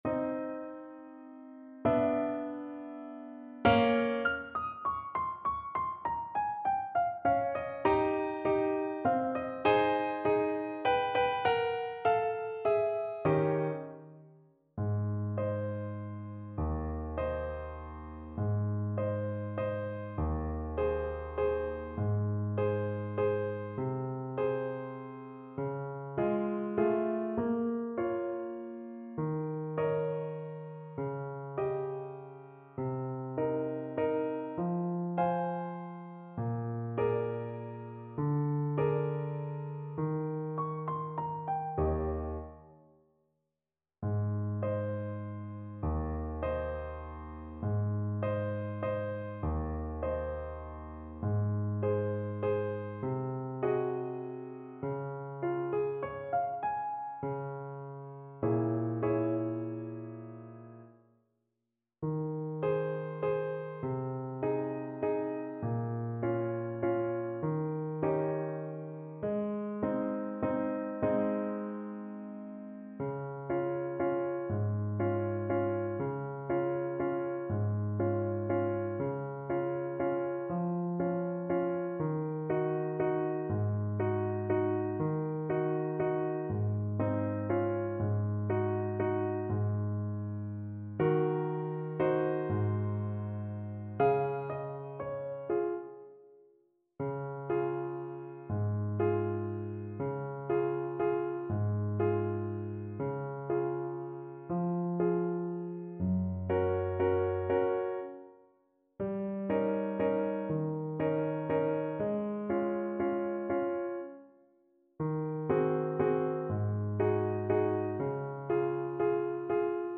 Alto Saxophone version
3/4 (View more 3/4 Music)
Jazz (View more Jazz Saxophone Music)